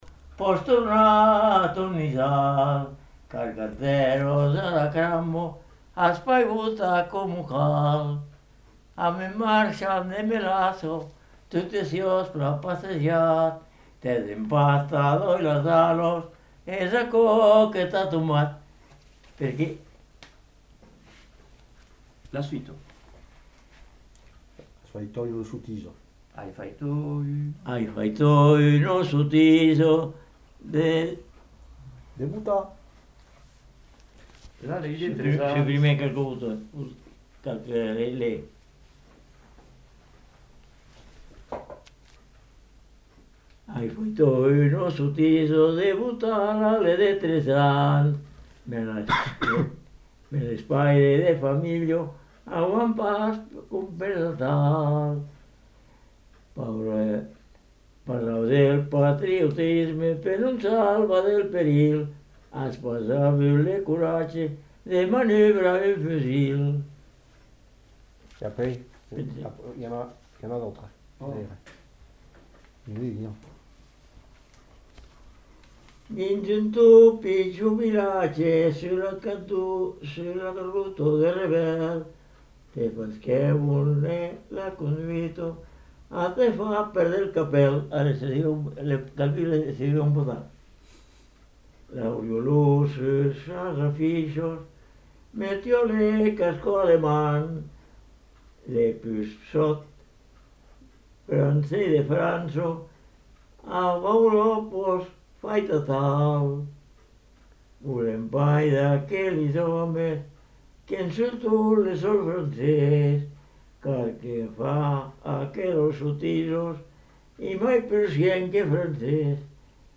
Lieu : Caraman
Genre : chant
Effectif : 2
Type de voix : voix d'homme
Production du son : chanté ; récité